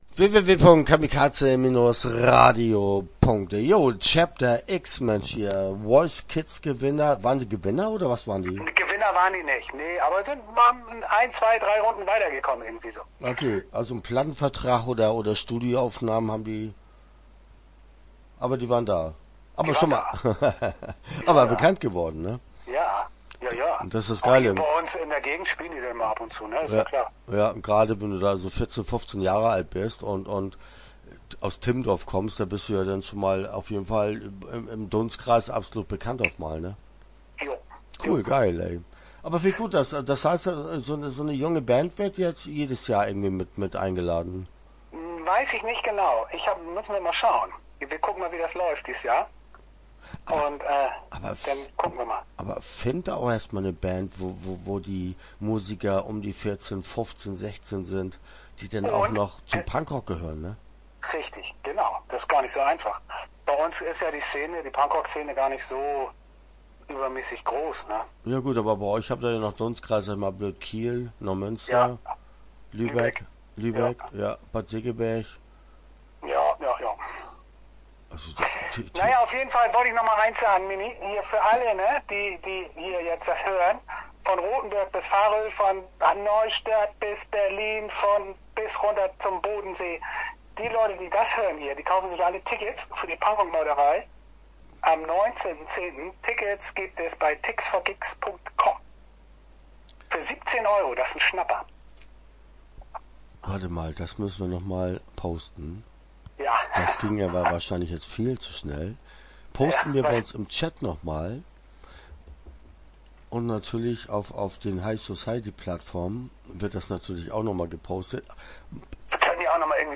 Punkrock-Meuterei - Interview Teil 1 (9:13)